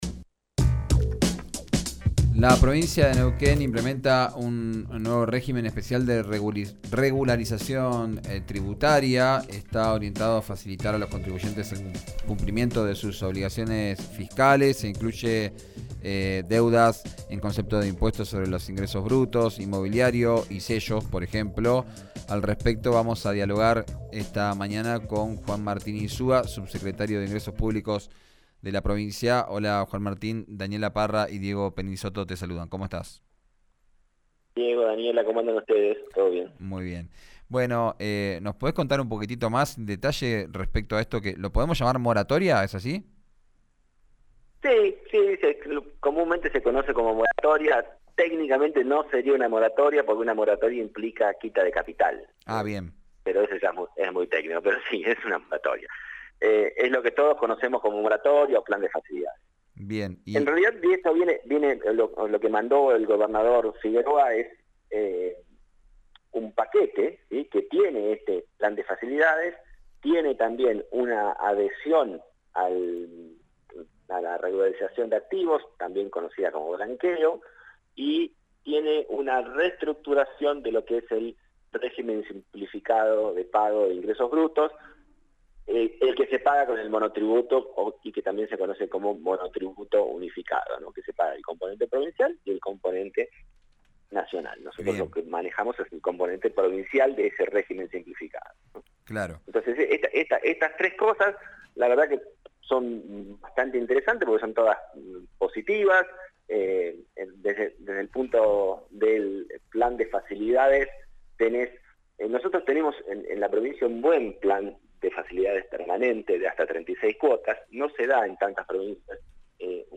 Escuchá a Juan Martín Insua, subsecretario de Ingresos Públicos de Neuquén, en RÍO NEGRO RADIO: